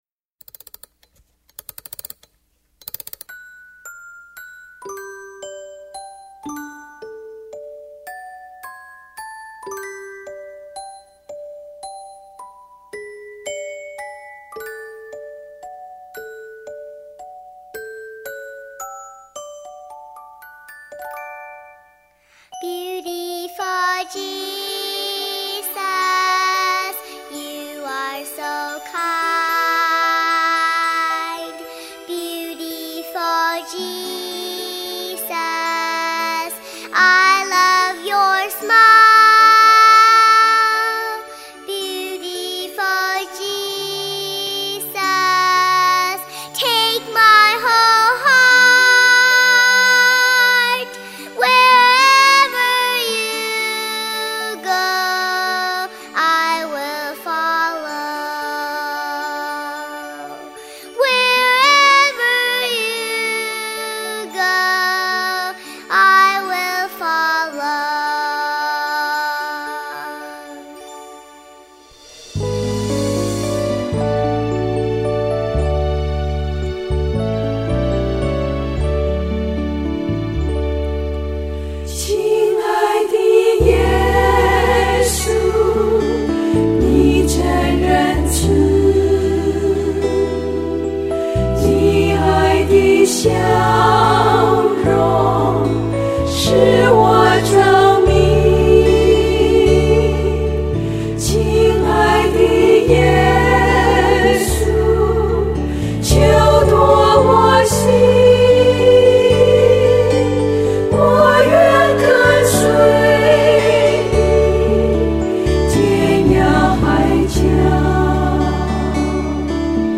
D調3/4